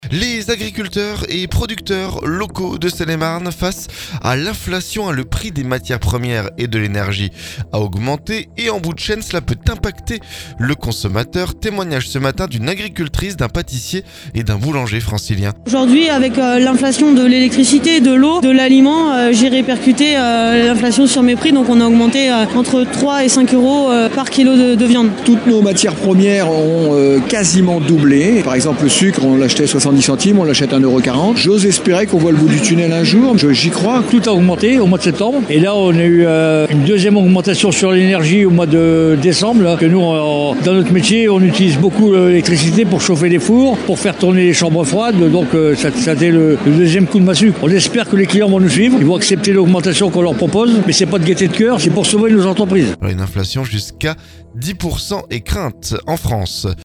Et en bout de chaîne cela peut impacter le consommateur. Témoignages ce jeudi d'une agricultrice, d'un pâtissier et d'un boulanger francilien.